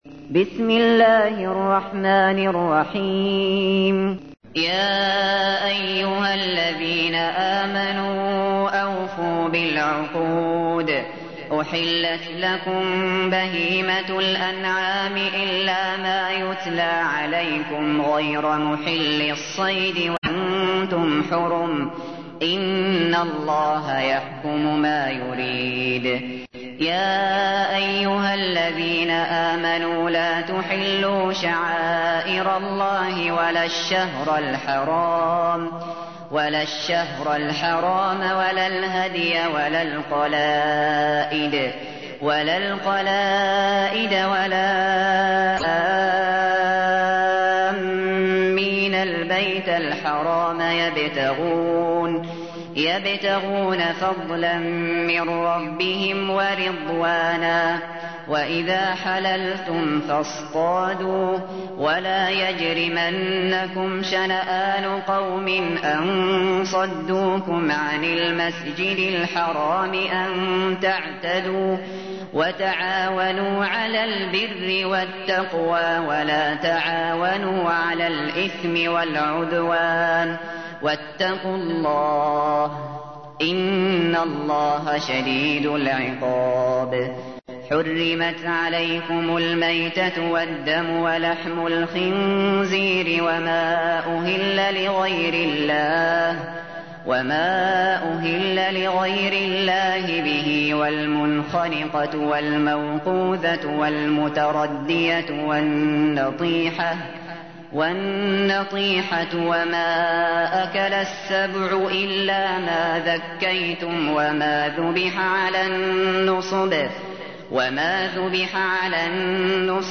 تحميل : 5. سورة المائدة / القارئ الشاطري / القرآن الكريم / موقع يا حسين